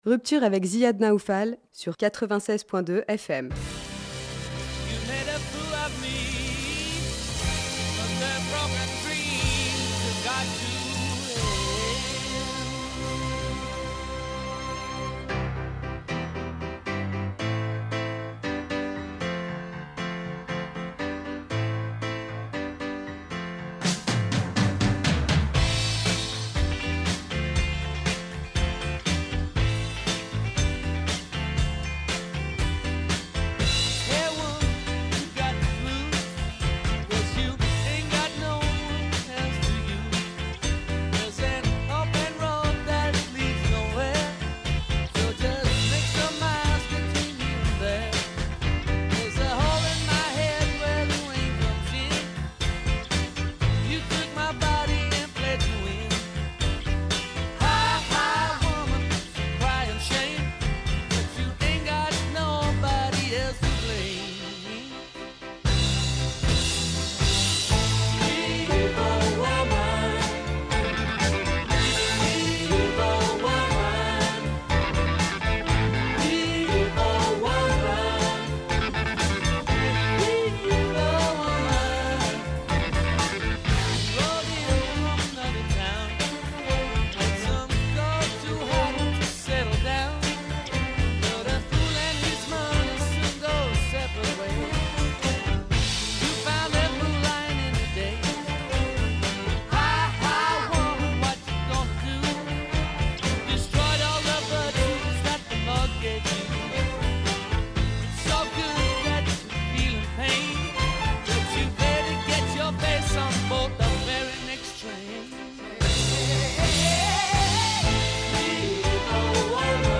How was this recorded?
Ruptures, on Radio Liban 96.2FM.